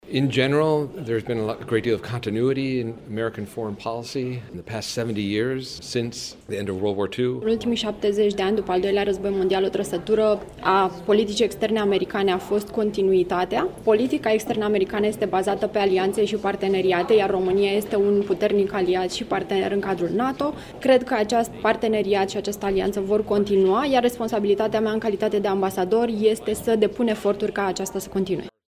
Indiferent de rezultatul scrutinului, parteneriatul Statelor Unite cu România va continua, dă asigurări ambasadorul american la Bucureşti, Hans Klemm: